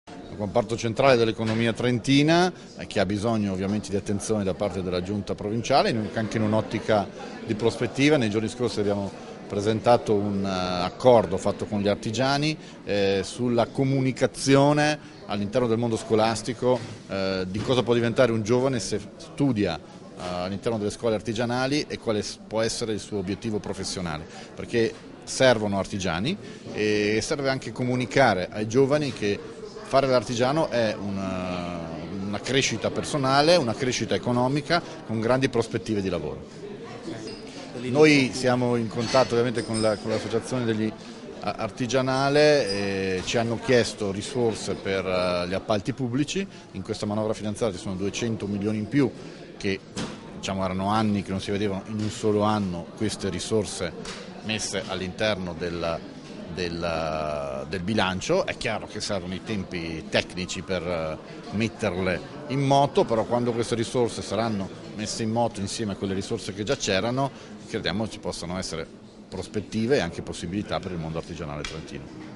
Il presidente Fugatti e l'assessore Failoni sono intervenuti oggi a Vezzano all'assemblea di categoria